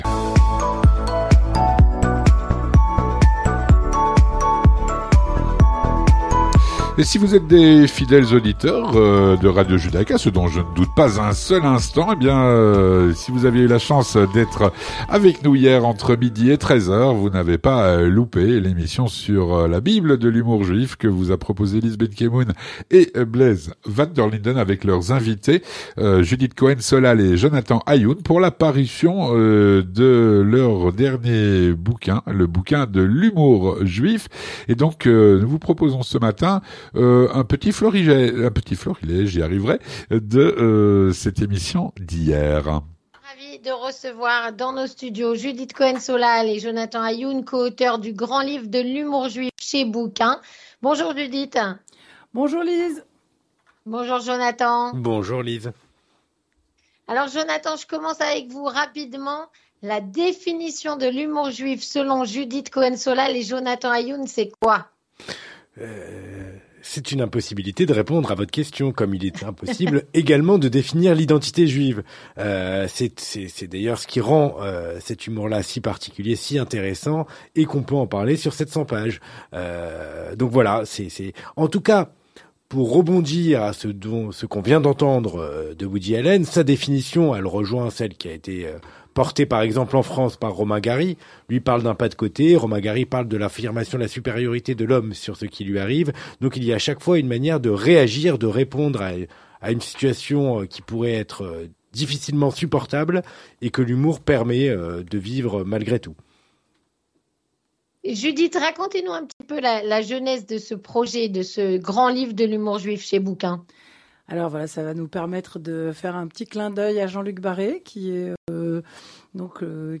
Extrait de l’édition spéciale Humour juif